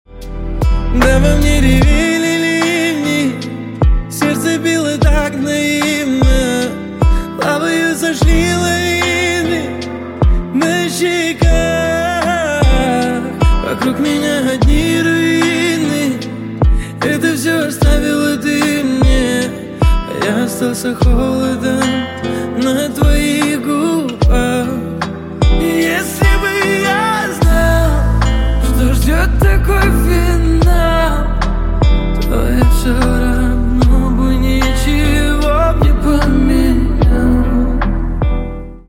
поп , романтические